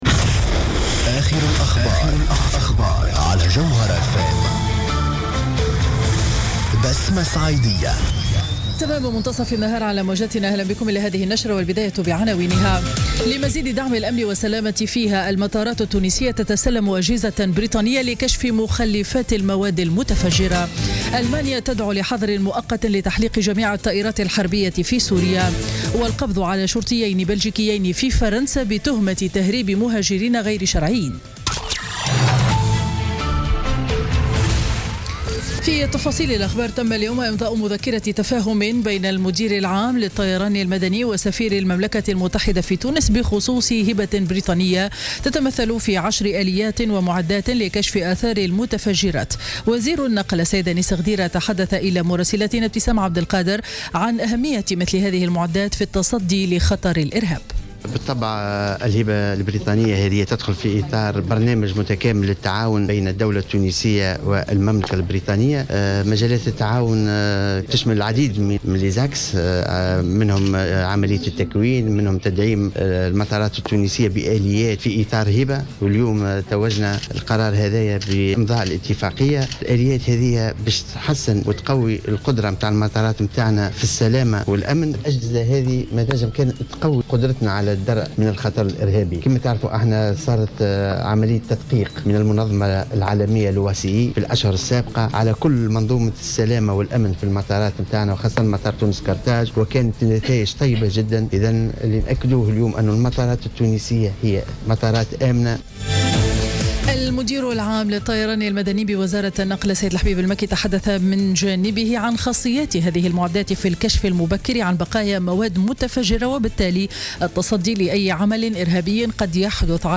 نشرة أخبار منتصف النهار ليوم الخميس 22 سبتمبر 2016